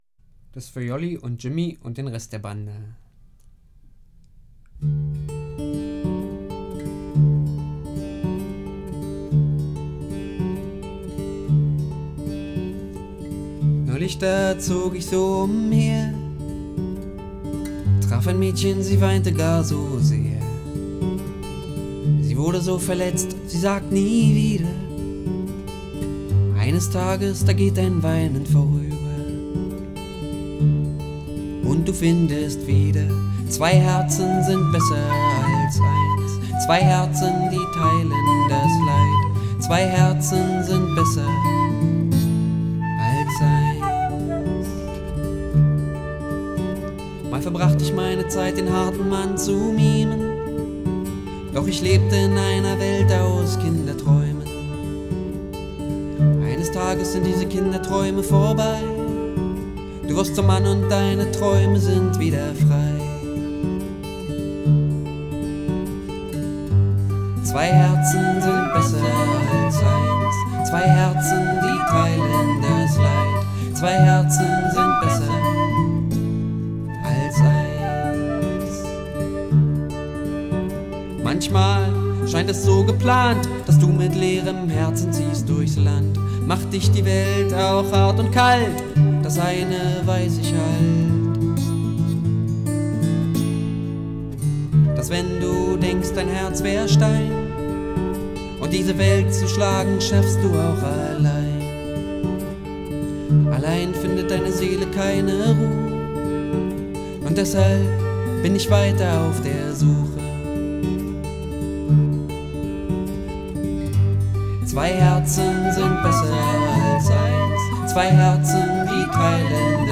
Gesang, Gitarre
Querflöte